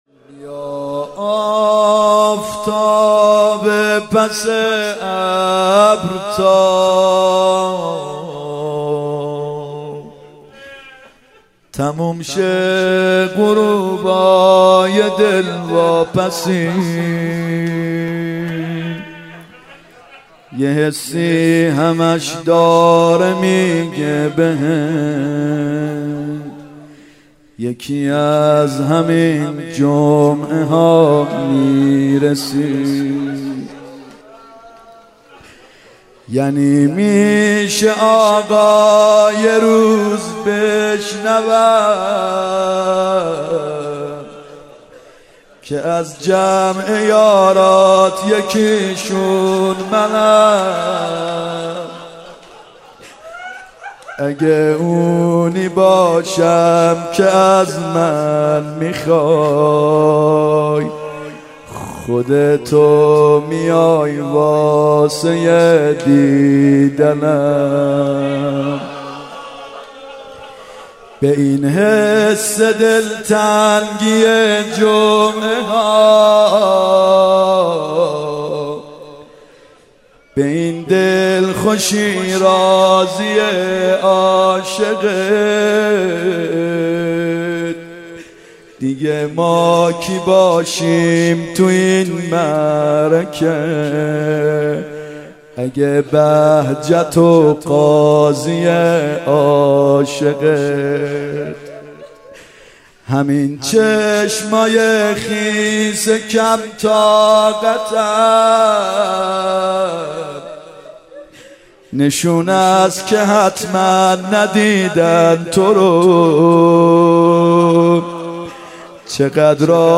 مرثیه و مداحی حضرت خديجه
2 بیا آفتاب پس ابر  (جدید،سال93،هیئت یا مهدی عج اسلامشهر)